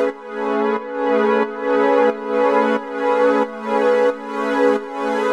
GnS_Pad-alesis1:4_90-A.wav